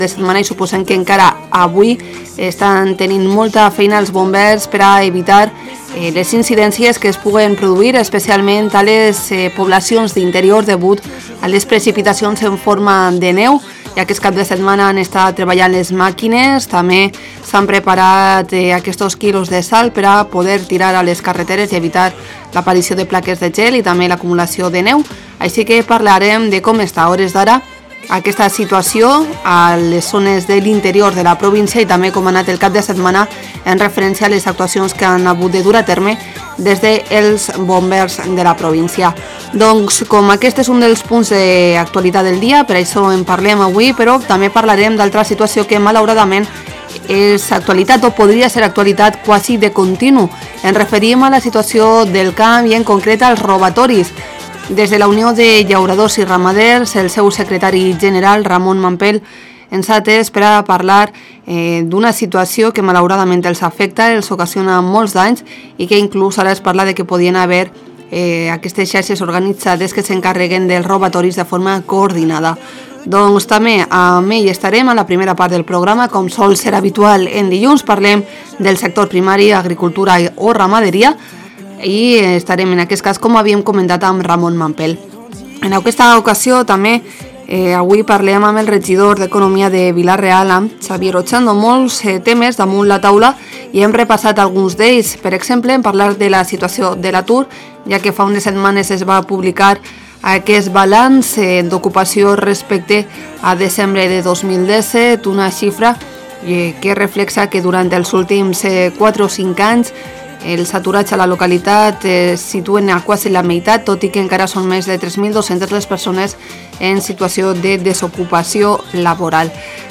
Por otra parte, hemos charlado con el diputado provincial de Bomberos, Luis Rubio, sobre el dispositivo de emergencia activado con motivo de las nevadas que afectan al interior de la provincia.
Por último, como cada lunes, hemos cerrado el programa con una opinión política. Hoy ha estado a cargo del edil socialista Álvaro Escorihuela.